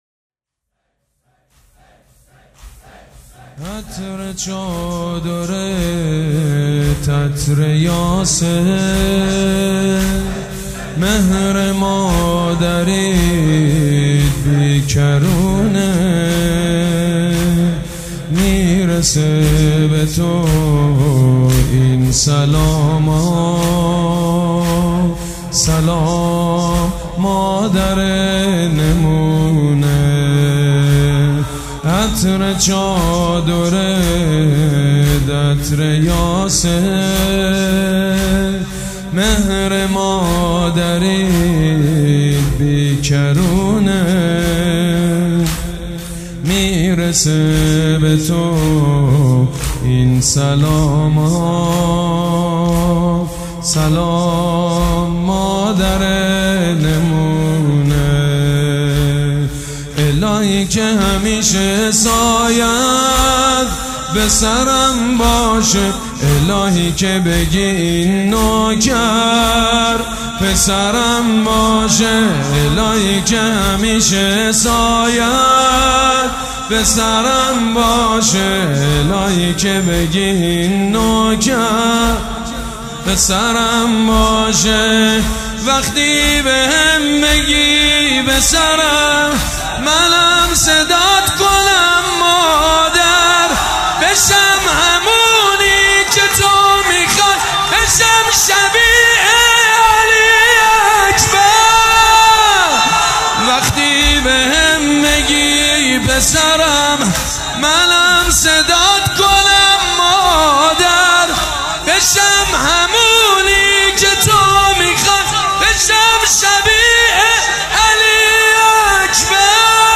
خیمه حضرت فاطمه زهرا سلام الله علیها
فاطمیه 96 - زمینه - مادر نمونه